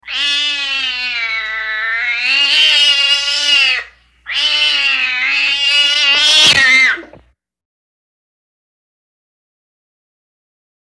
Звуки агрессивной кошки
Злая кошка вот-вот вонзит свои когти